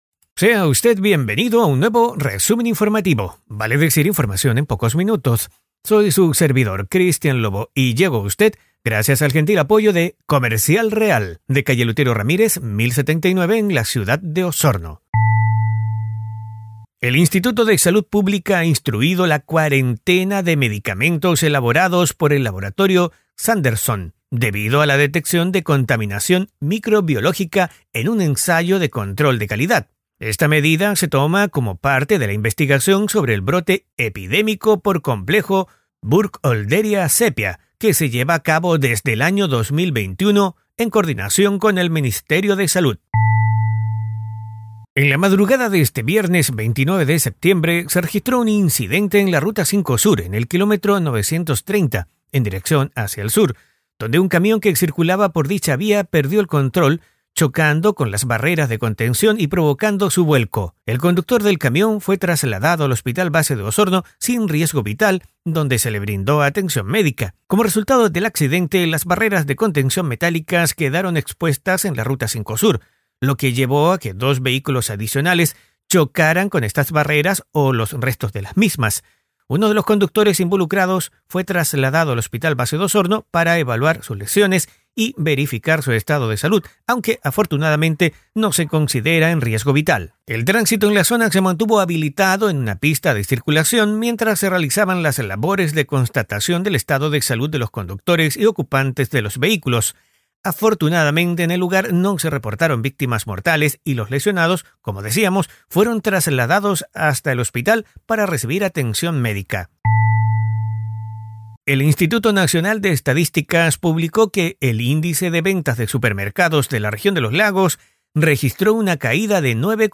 Este audio podcast te trae un resumen rápido y conciso de una decena de noticias enfocadas en la Región de Los Lagos.